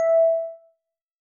Techmino/media/sample/bell/20.ogg at dd8a810591abbdafa39809de2bc2013c55bb1c82